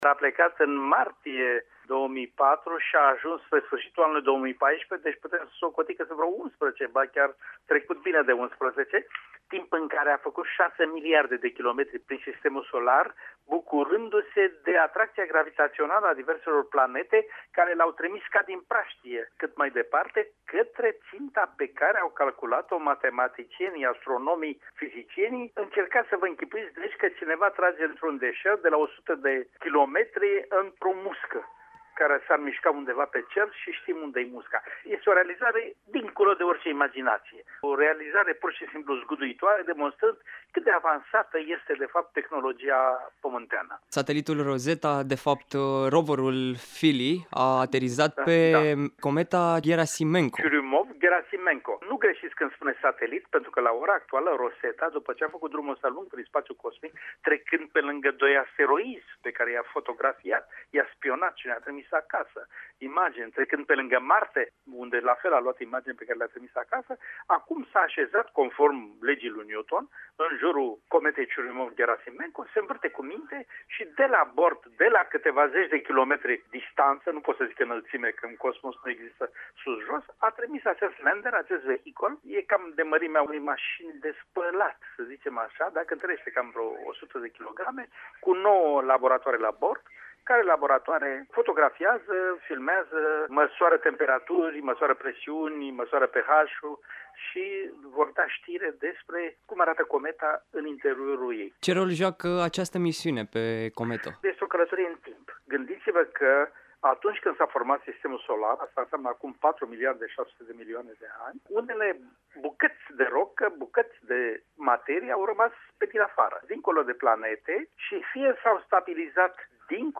O realizare care transforma science-fiction-ul in realitate, despre al carei scop aflăm din interviul